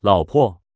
Here is what it sounds like now, at least the p is more clear.